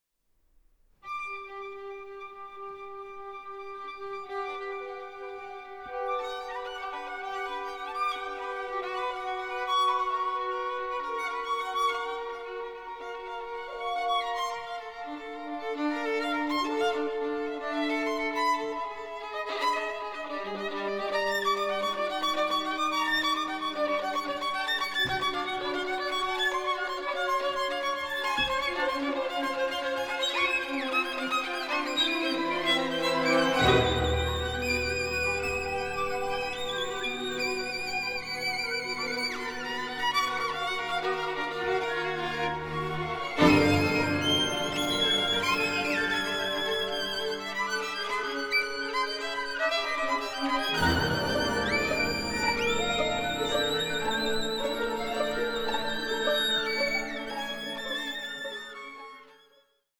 With orchestral textures both radiant and unsettling